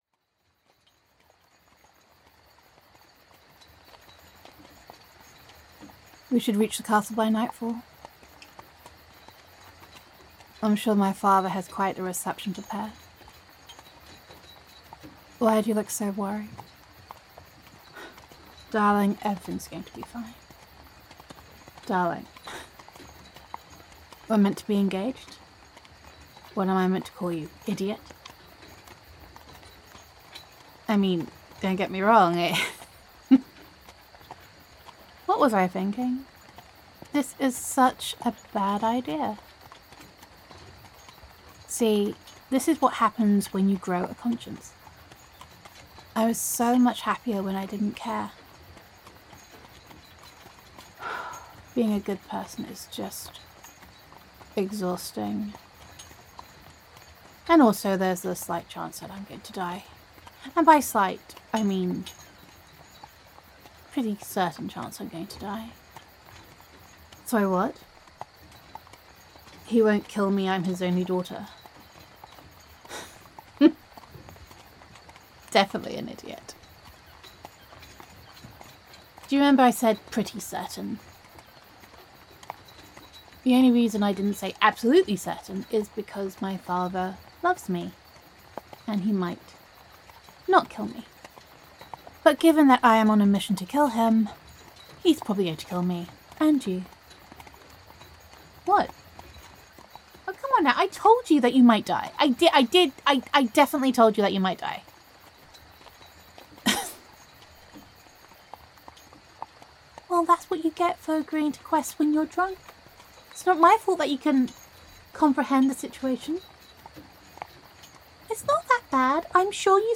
[F4A] Stupid Inconvenient Conscience